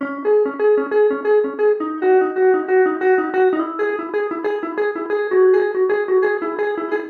14 Boiling In Dust Guitar Long.wav